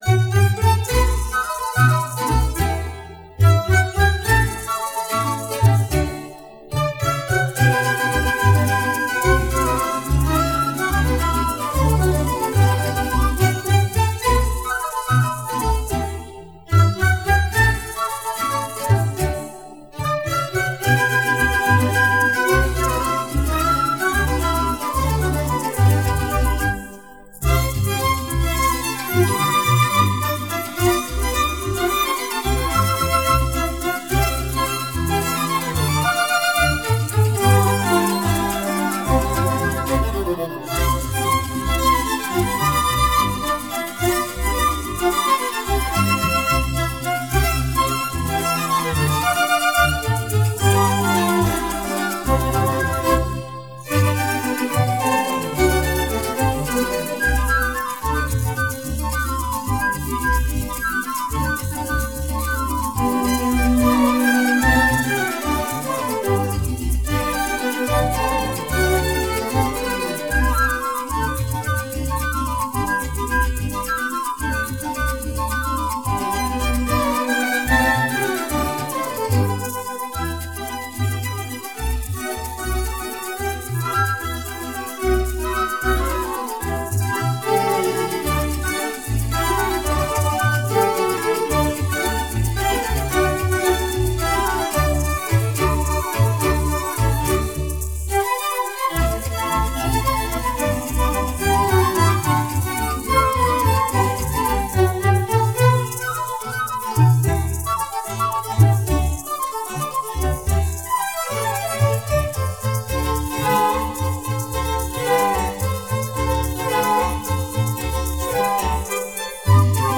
Música latina
La música de América Latina.